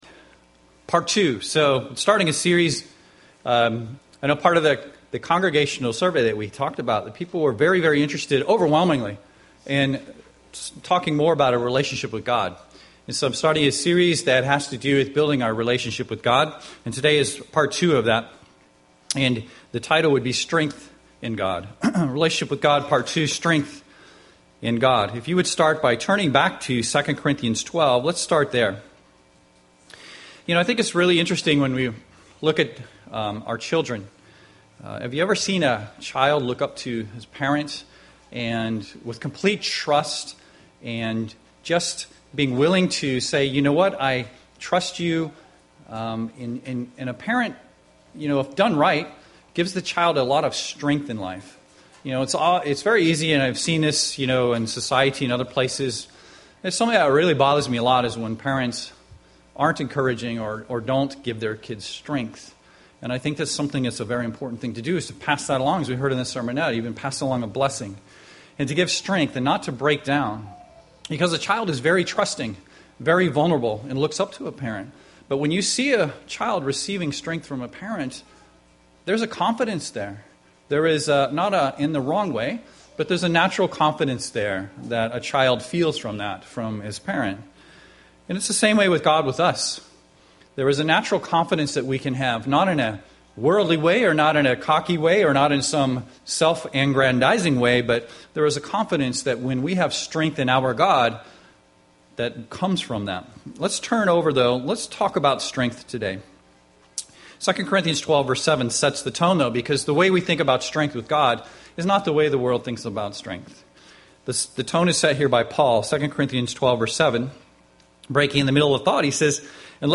Given in Seattle, WA
Print UCG Sermon